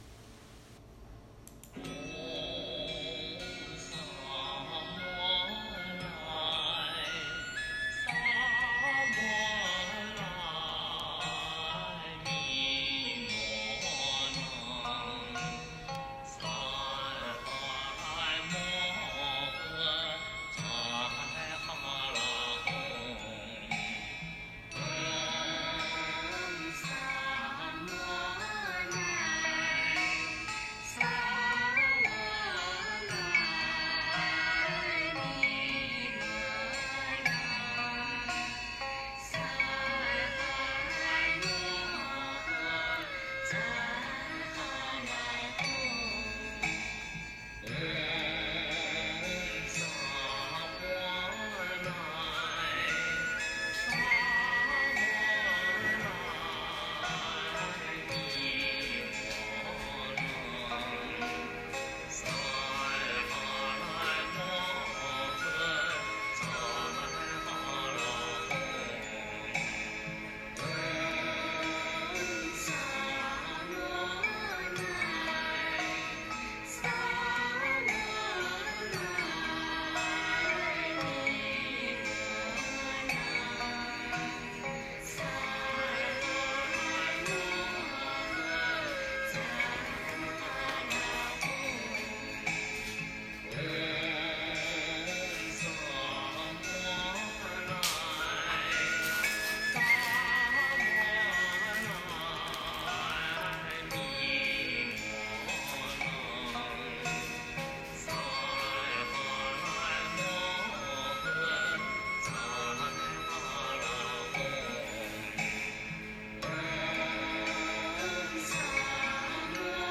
《吉祥偈》佛教音乐